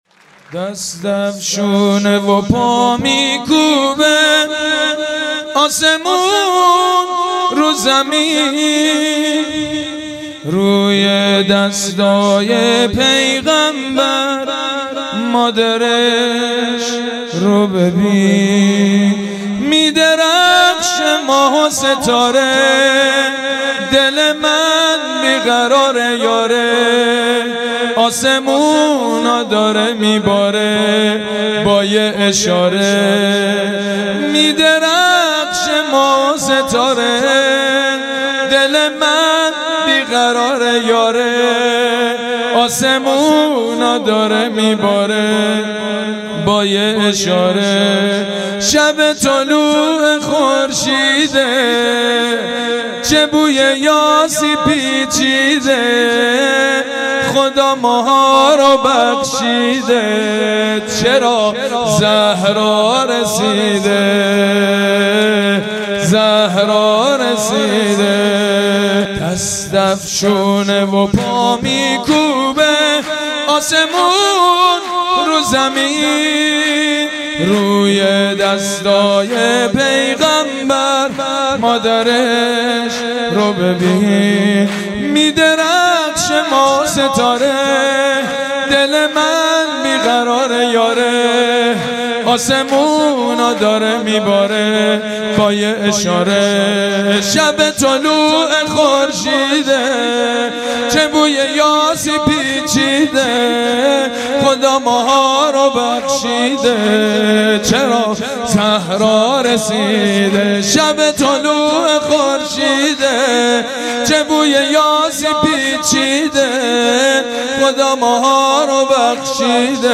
مولودی خوانی